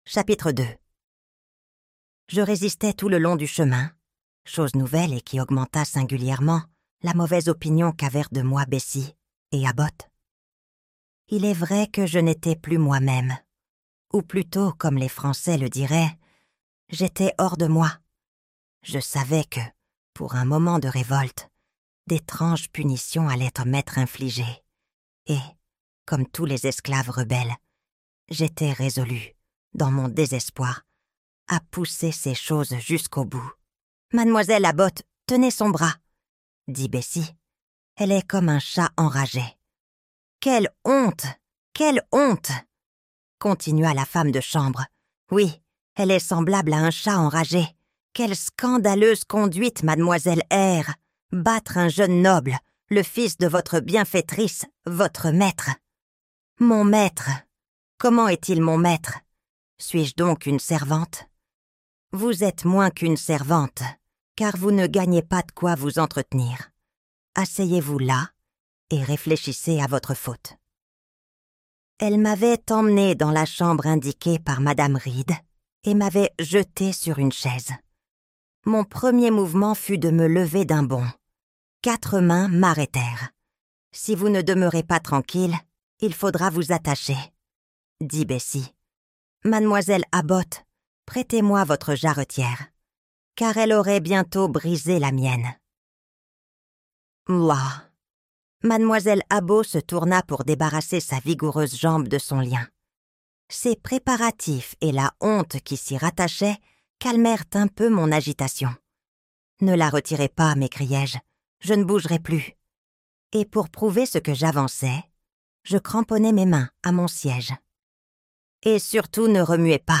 Jane Eyre - Livre Audio